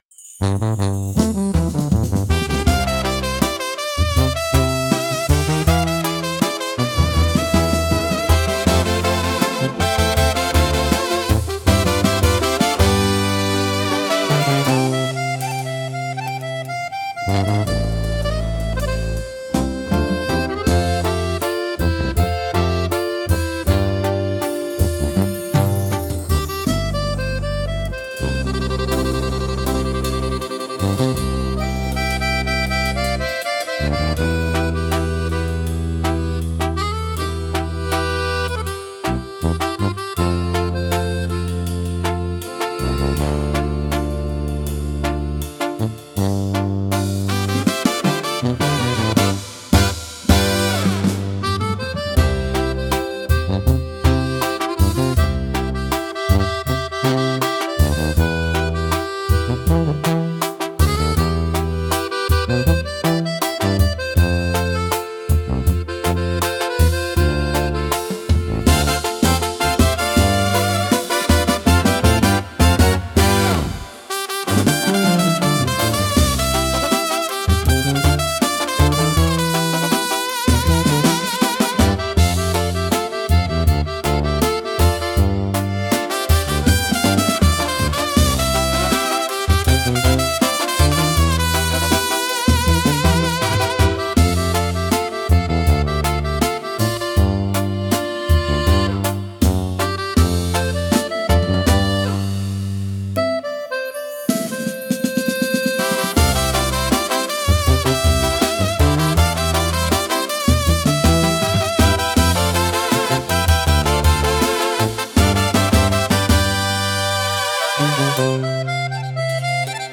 instrumental 7